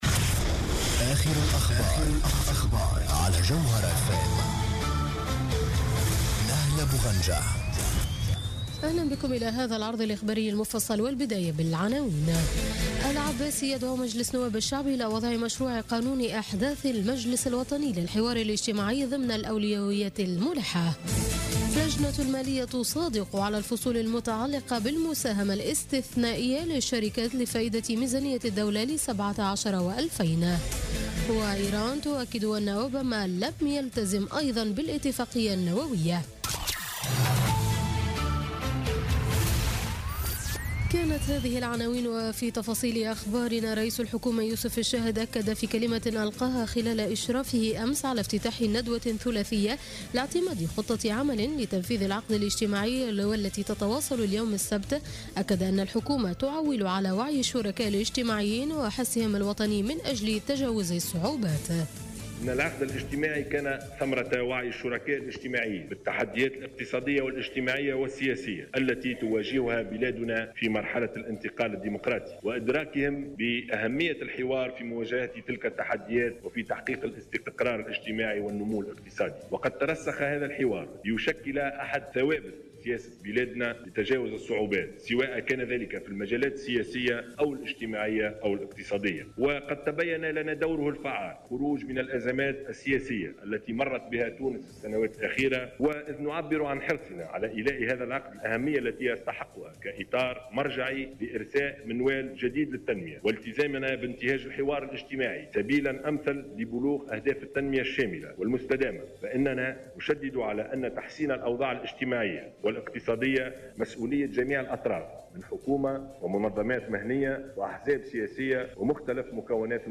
نشرة أخبار منتصف الليل ليوم السبت 12 نوفمبر 2016